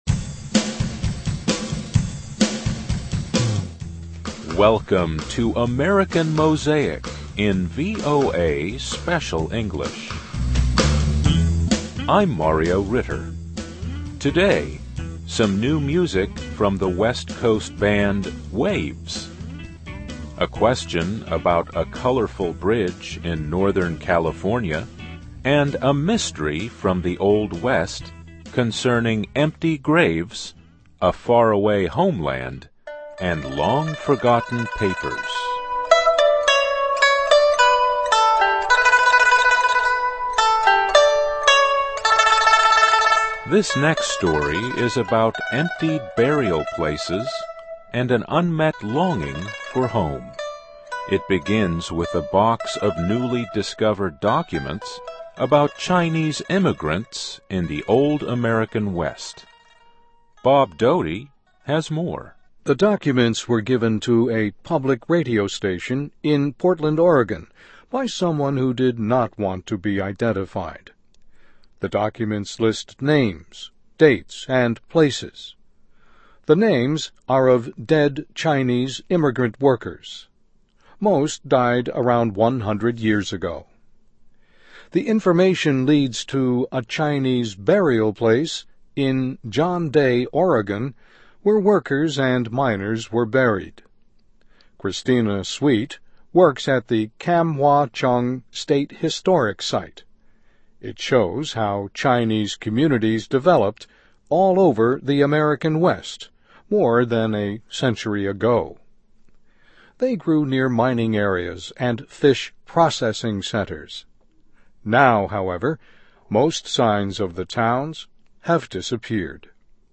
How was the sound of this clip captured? Welcome to American Mosaic in VOA Special English.